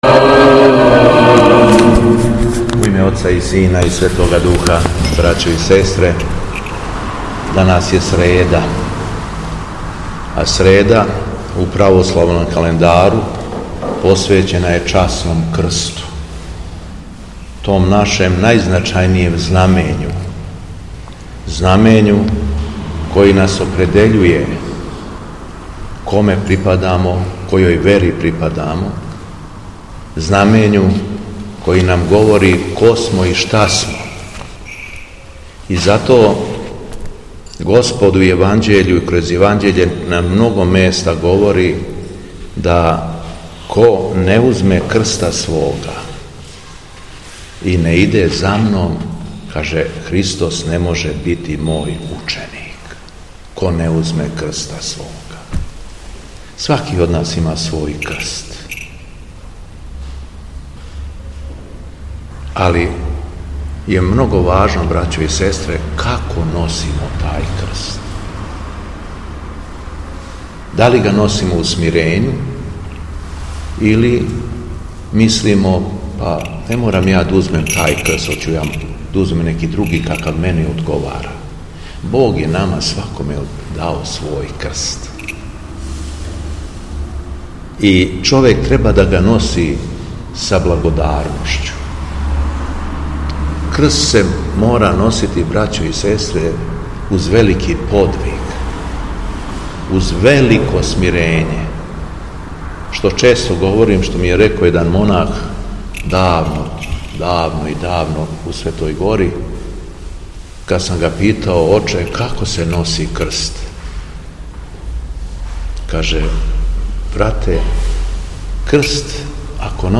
Беседа Његовог Високопреосвештенства Архиепископа крагујевачког и Митрополита шумадијског г. Јована